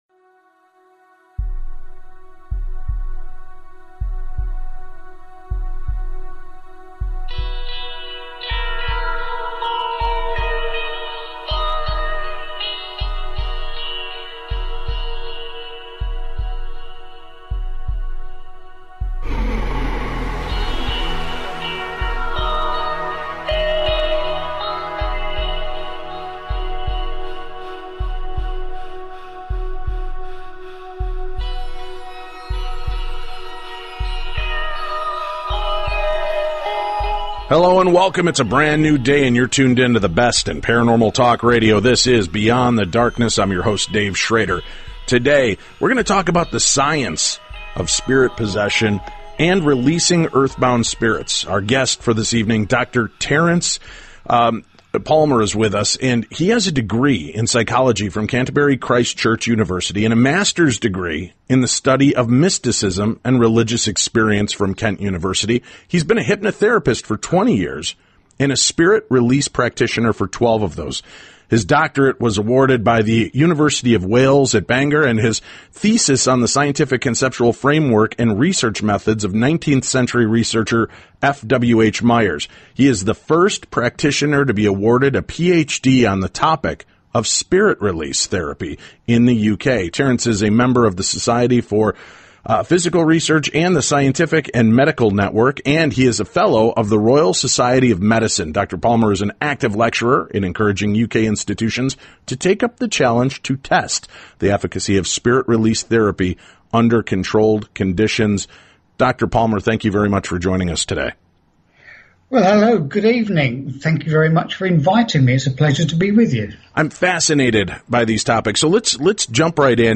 Talk Radio